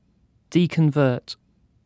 Ääntäminen
Synonyymit apostate Ääntäminen UK UK : IPA : /diːˈkɒnvɜː(ɹ)t/ IPA : /diːkənˈvɜː(ɹ)t/ Haettu sana löytyi näillä lähdekielillä: englanti Käännöksiä ei löytynyt valitulle kohdekielelle.